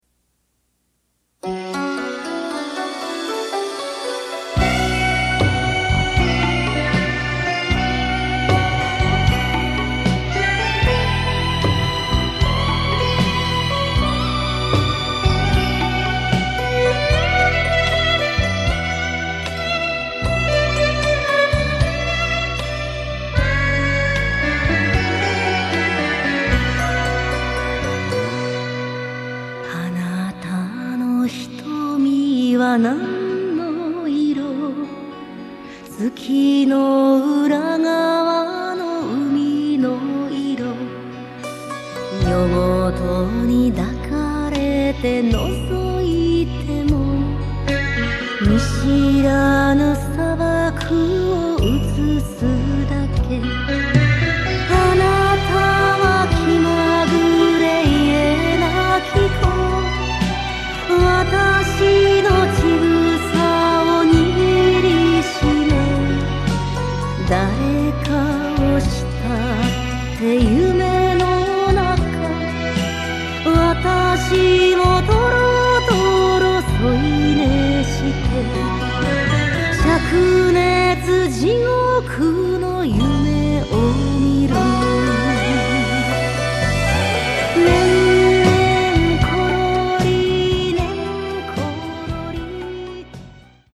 mp3はラジカセ(東芝 TY-CDX92)で作成しました。
mp3音源サンプル(A面冒頭)
歌入り カラオケ